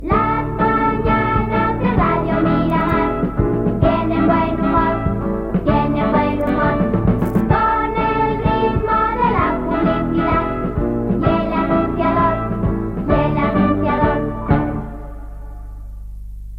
Indicatiu cantat del programa